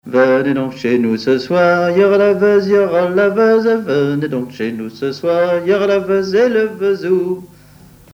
cornemuse
branle
Couplets à danser
Pièce musicale inédite